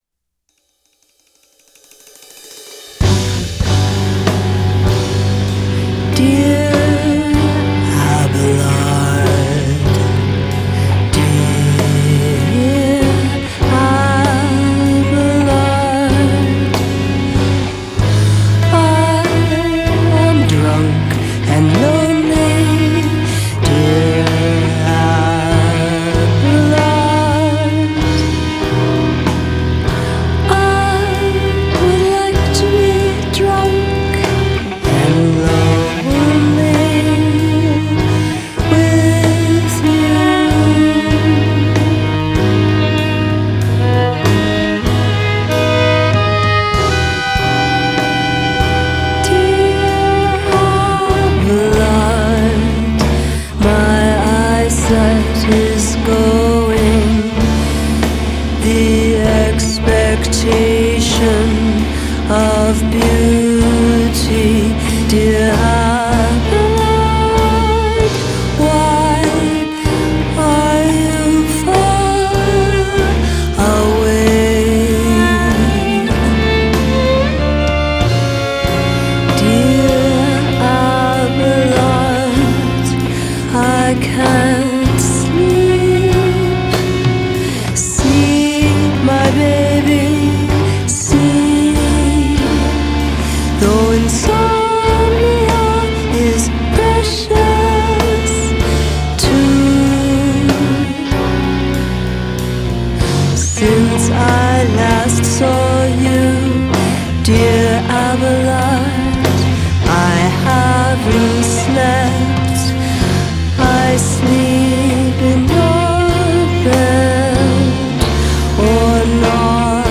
saxophone
mandolin